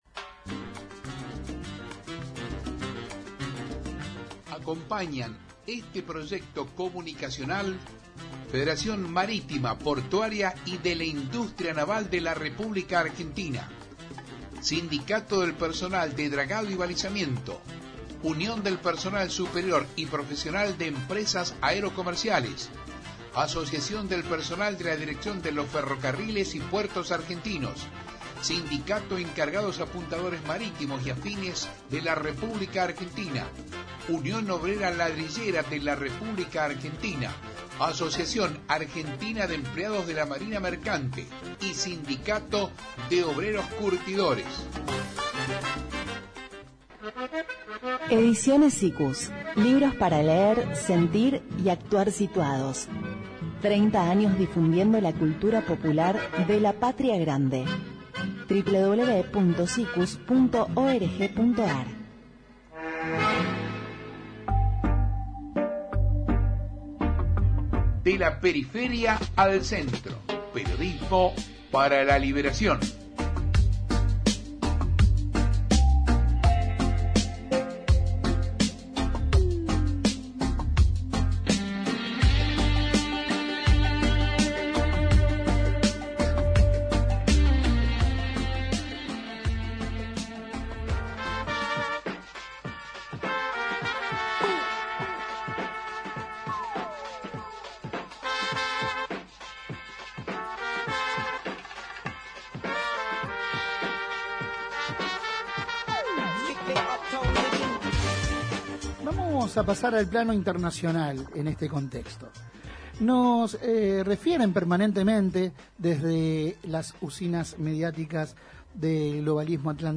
Compartimos la entrevista completa: 25/04/2023